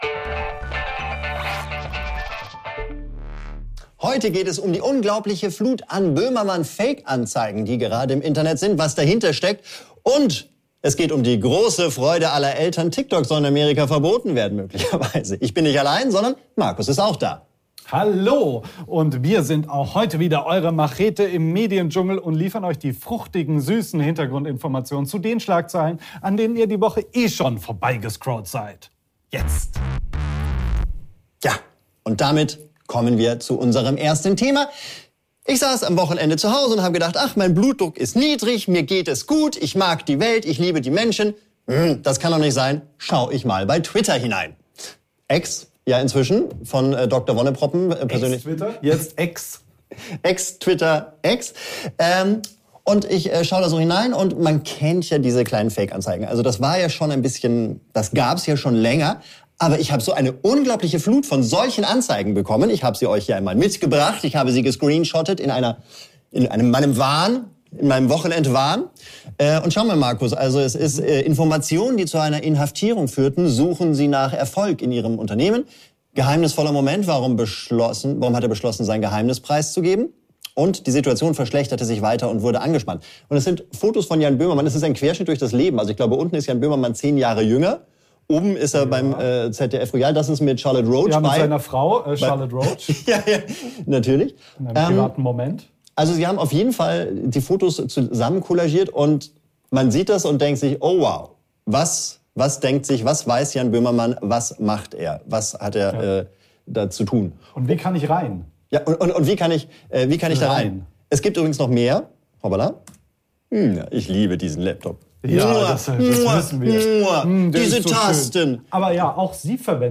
Wir wollen euch Live über unsere Lieblingsthemen des Tages informieren.